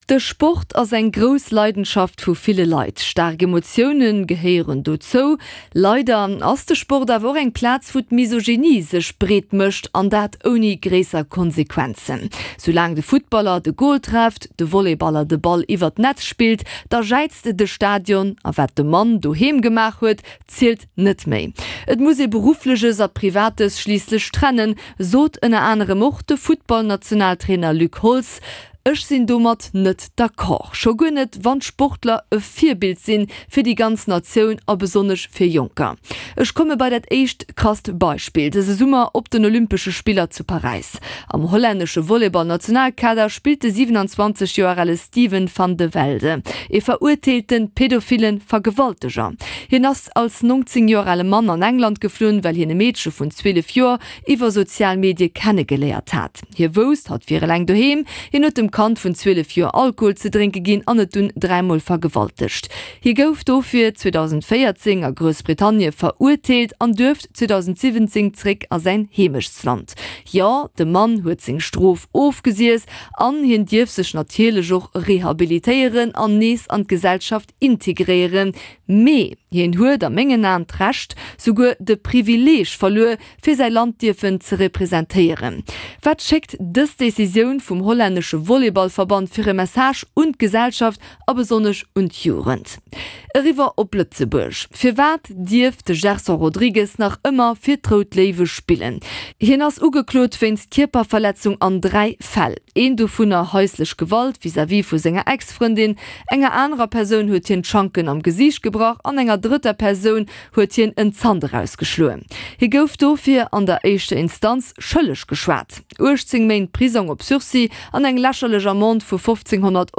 Commentaire.